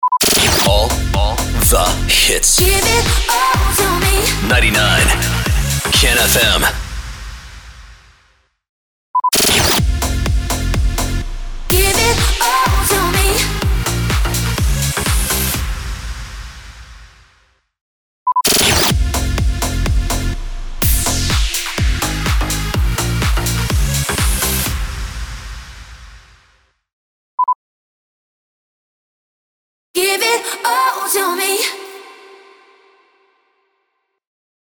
434 – SWEEPER – GIVE IT ALL TO ME
434-SWEEPER-GIVE-IT-ALL-TO-ME.mp3